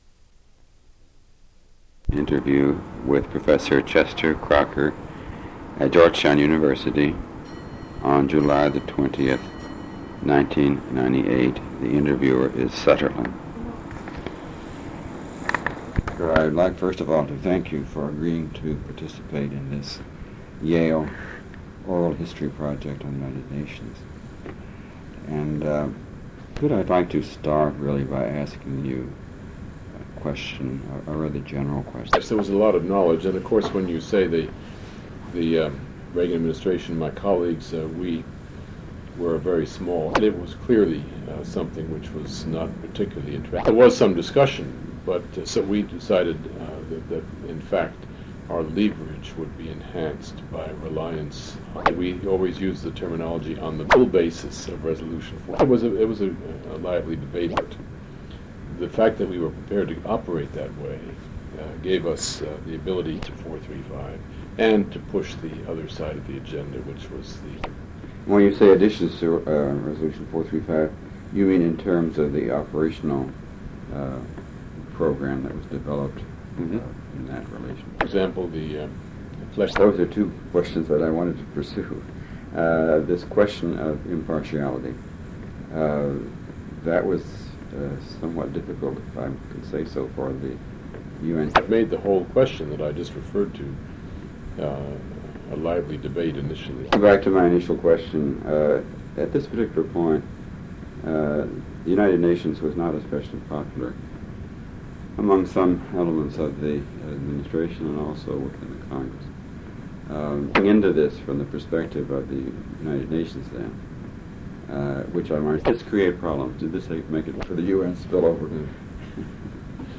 Interview with Chester Crocker, July 20, 1998 /